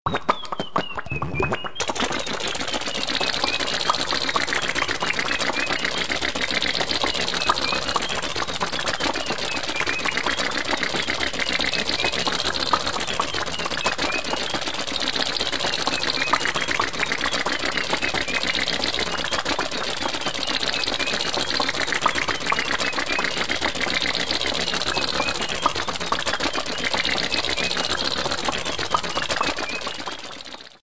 Мультяшная машинка едет с забавным звуком